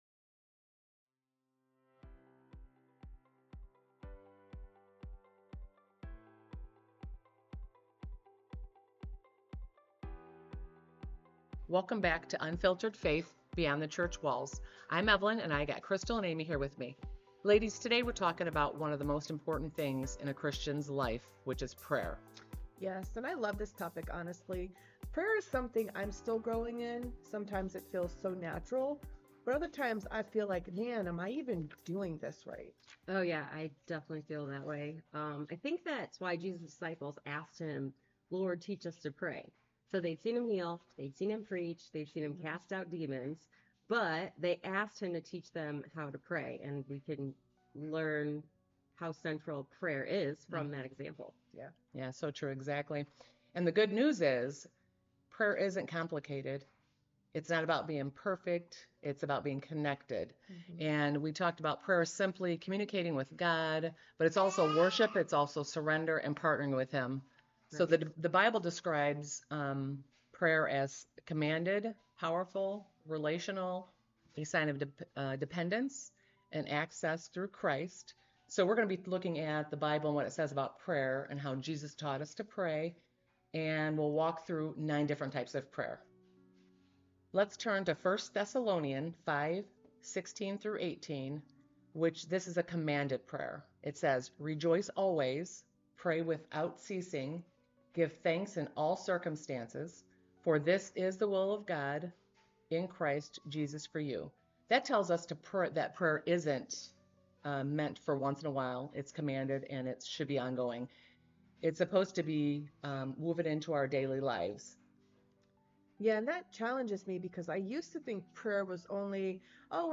From adoration and thanksgiving to intercession and spiritual warfare, this conversation is both practical and powerful.